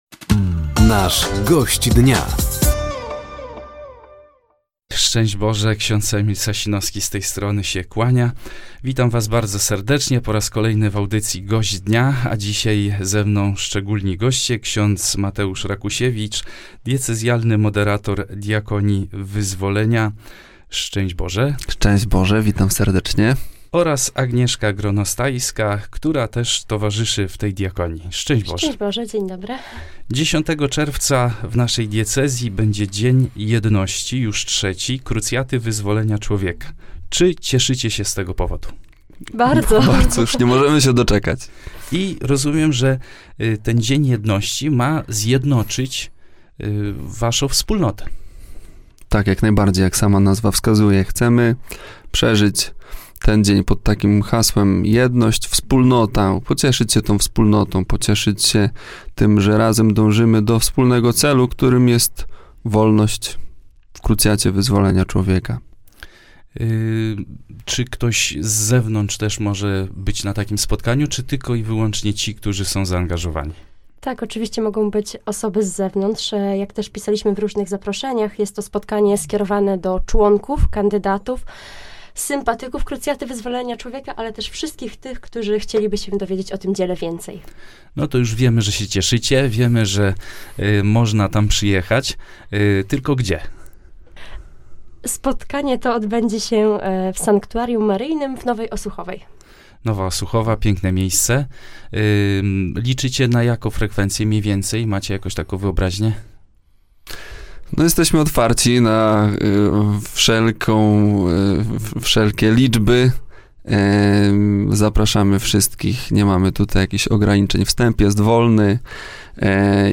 Rozmowa dotyczyła III. Dnia Jedności Krucjaty Wyzwolenia Człowieka, który odbędzie się 10 czerwca w Nowej Osuchowej.